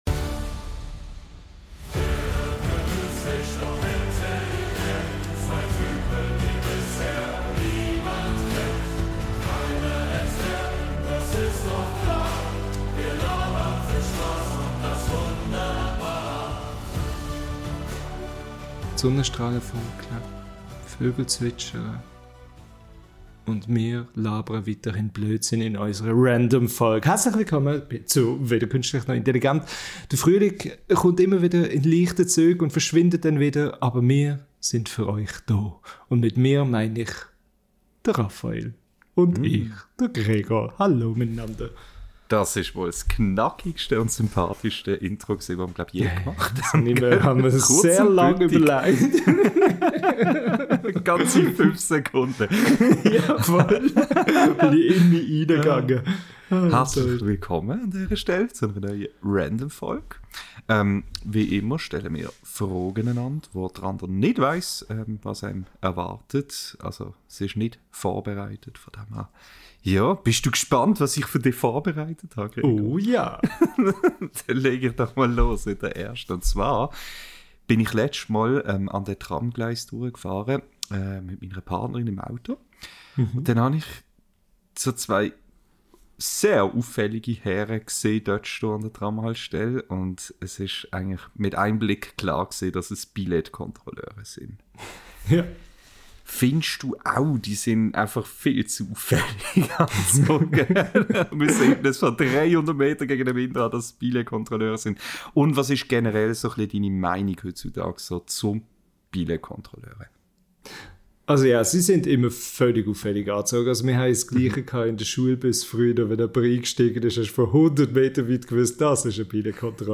Zwischen Chaos, Humor und überraschend ehrlichen Momenten entsteht wieder genau das, was Random ausmacht: Zwei Stimmen, null Vorbereitung und eine Reise von komplett absurd bis unerwartet tiefgründig.